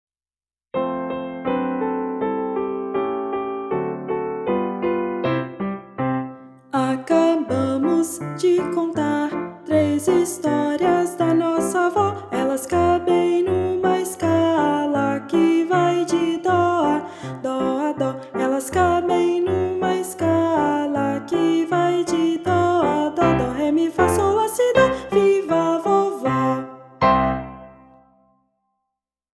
Voz Guia 2